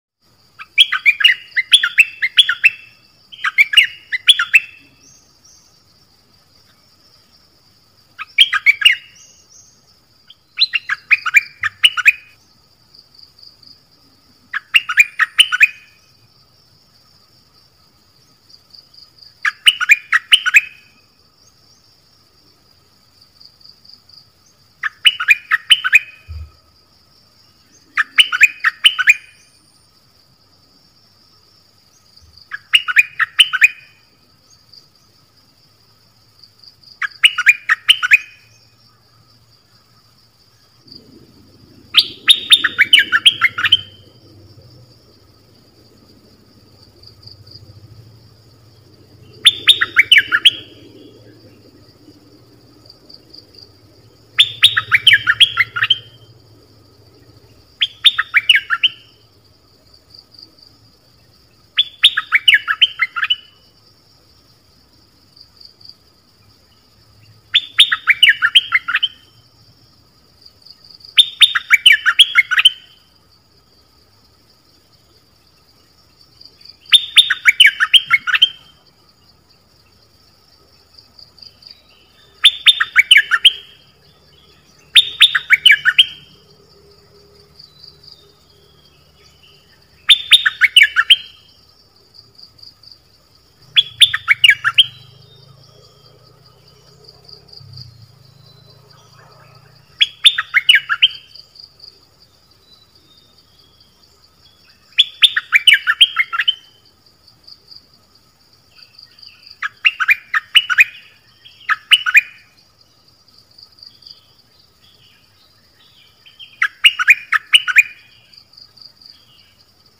คุณสามารถดาวน์โหลดเสียงนกดอกแตงในรูปแบบไฟล์ mp3 ได้ โดยเสียงนกดอกแตงจะมีเพลงเบิ้ล เปิดให้ลูกนก-ลูกเสอร้องตาม ใช้ต่อนกป่า เปิดให้นกคึก
เสียงนกดอกแตง เพลงเบิ้ล เปิดให้ลูกนก-ลูกเสอร้องตาม ใช้ต่อนกป่า เปิดให้นกคึก